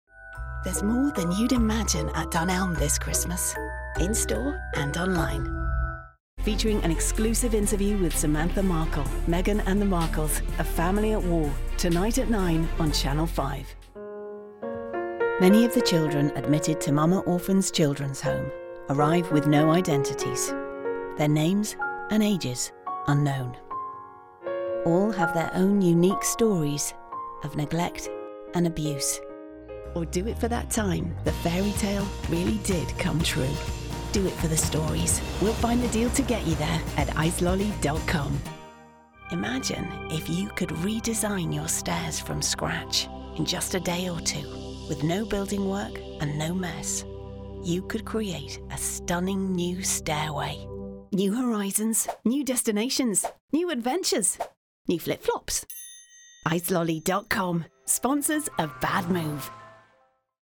TV Showreel